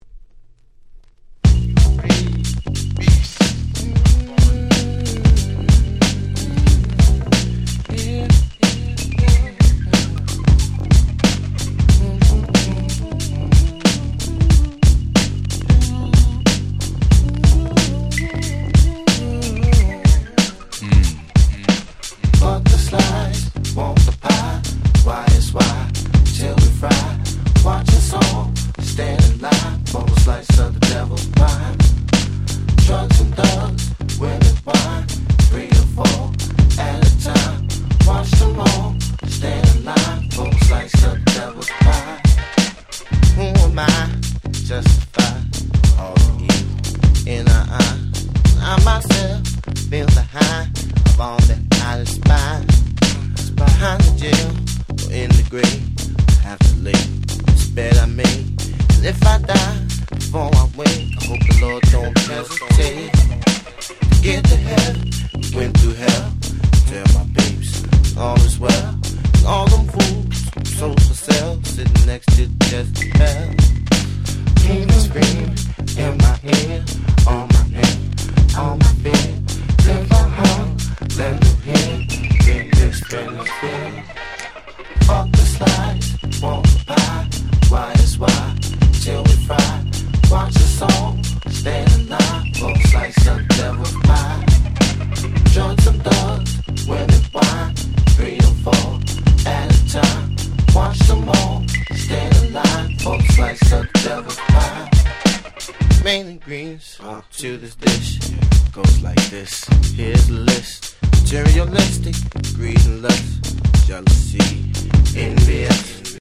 98' Smash Hit R&B/Neo Soul !!
ヒップホップソウル